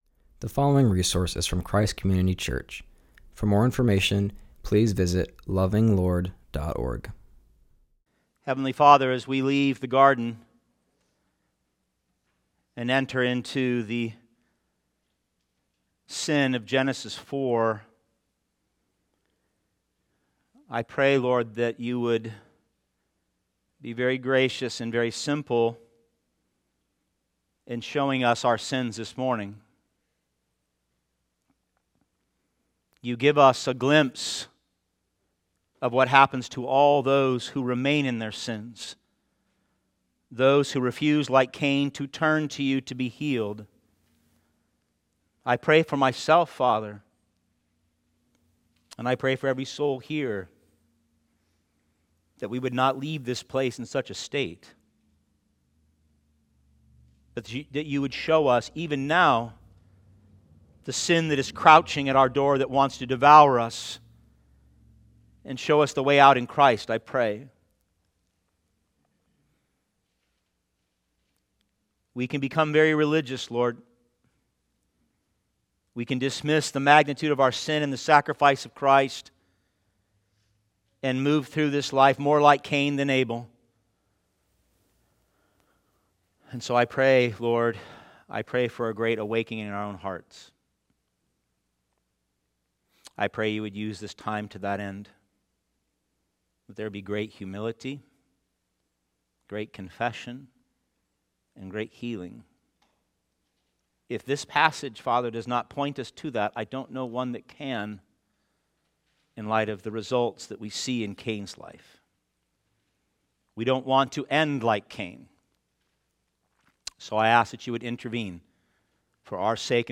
continues our series and preaches from Genesis 4:1-12.